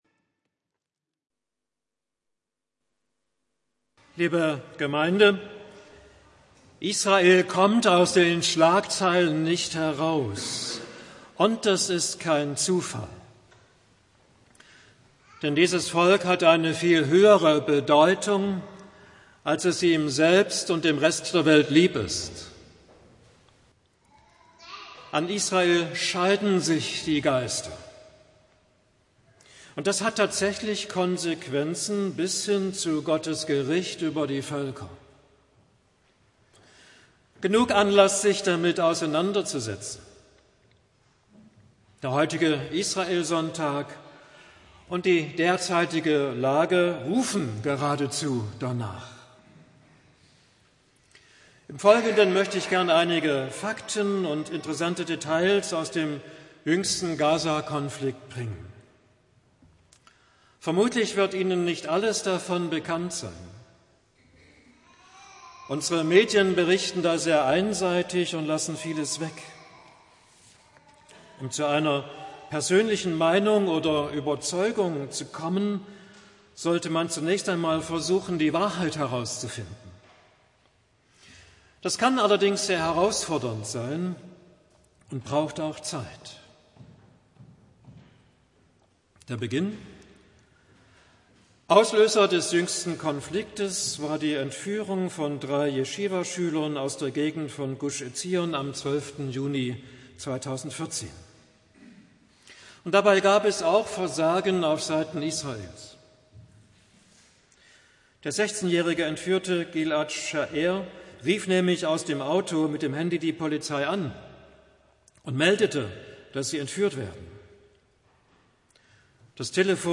Mai 2015 Heruntergeladen 1764 Mal Kategorie Audiodateien Predigten Schlagwörter Israel , hamas , gaza Beschreibung: Perspektive Sommer 2014